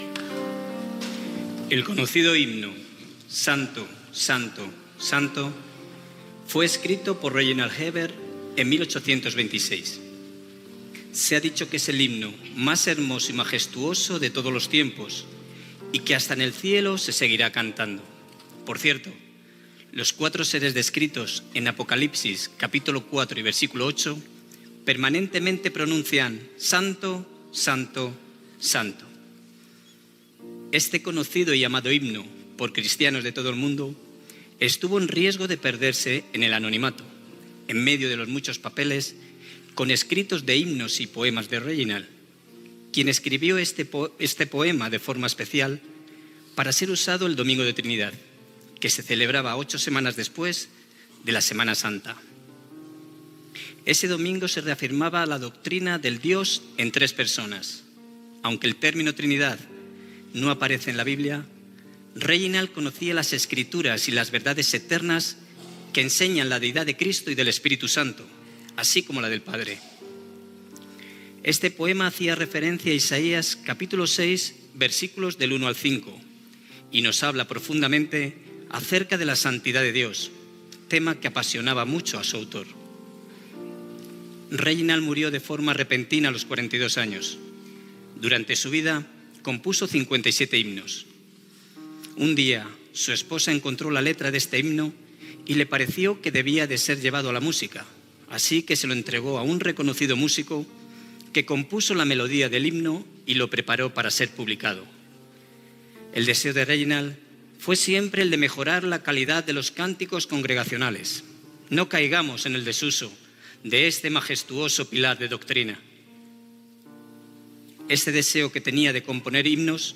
d5614ef177dc0b01389bab9f206e6c905ece07a7.mp3 Títol Radio Luz a las Naciones Emissora Radio Luz a las Naciones Titularitat Tercer sector Tercer sector Religiosa Descripció L'himne evangèlic "Santo, santo, santo…"